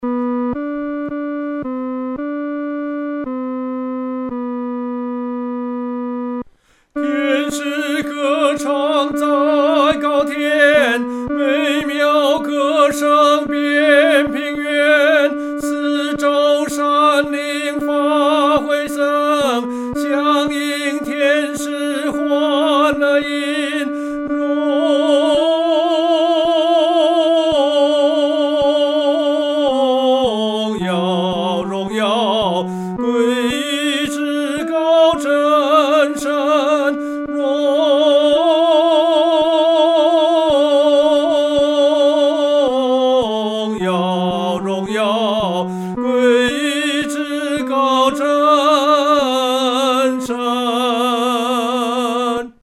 独唱（第三声）
Traditional French Carol,1855